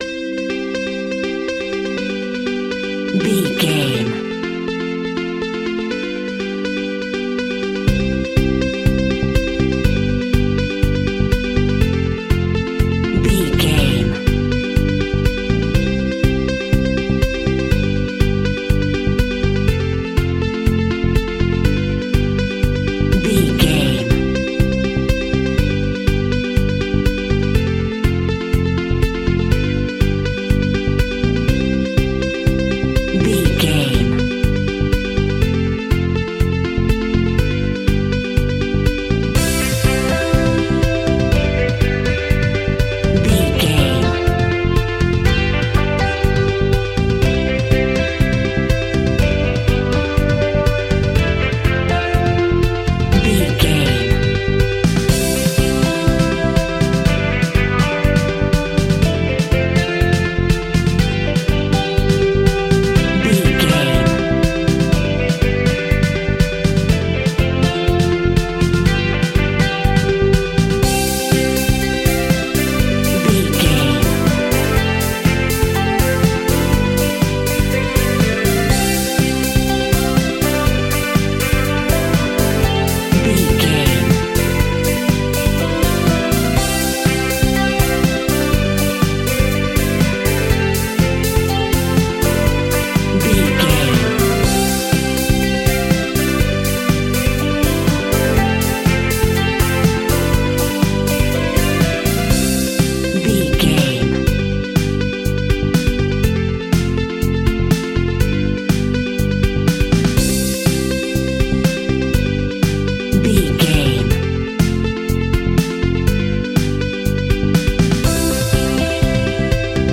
Business Retro 90s Pop Rock.
Ionian/Major
indie pop
fun
energetic
uplifting
cheesy
instrumentals
upbeat
groovy
guitars
bass
drums
piano
organ